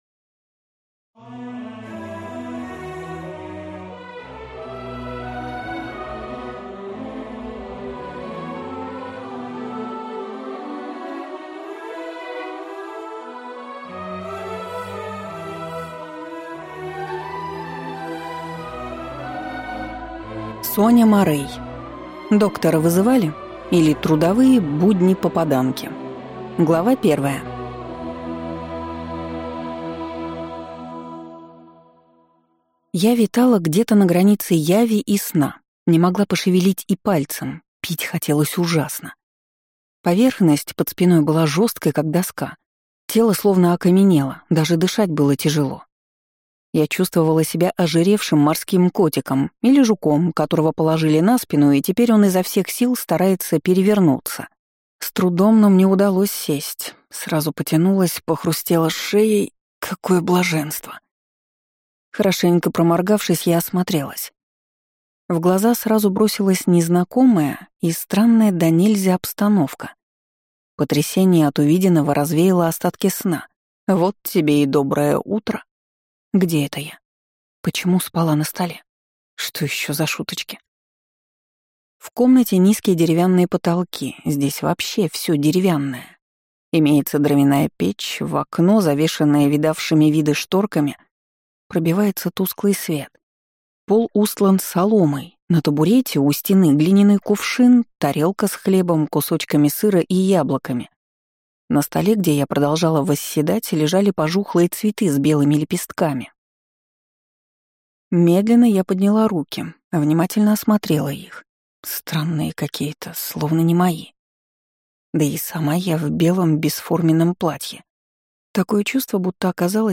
Аудиокнига Доктора вызывали? или Трудовые будни попаданки | Библиотека аудиокниг